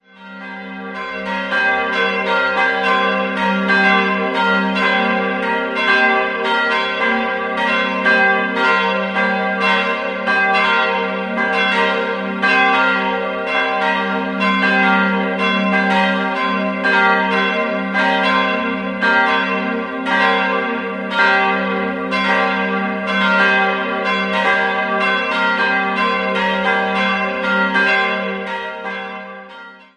3-stimmiges TeDeum-Geläute: g'-b'-c'' Die große und kleine Glocke wurden im Jahr 1953 von F. W. Schilling in Heidelberg gegossen, die mittlere ist unbezeichnet, stammt aber wohl von Sebald Beheim I (Nürnberg) und entstand Anfang des 16. Jahrhunderts.